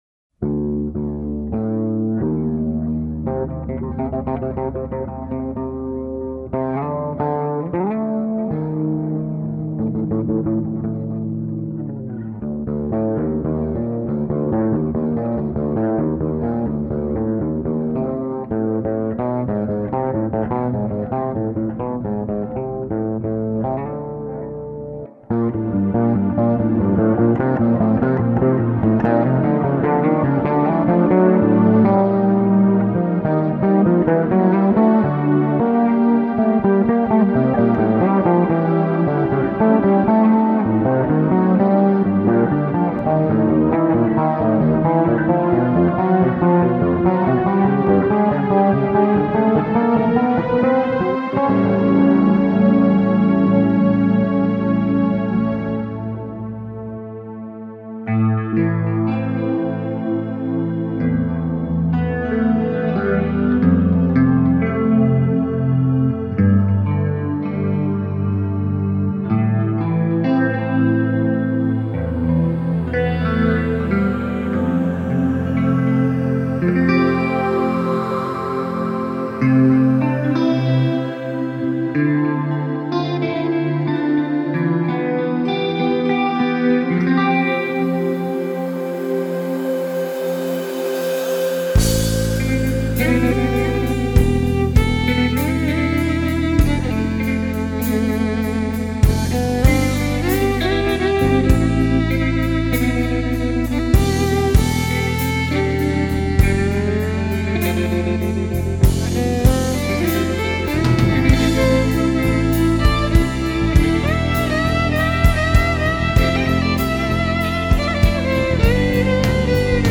Итальянская группа прогрессивного рока